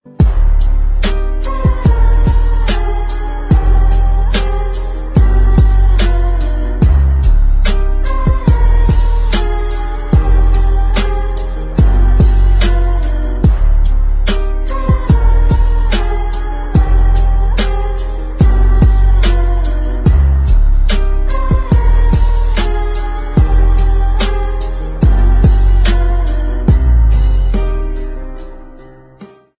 Musica Triste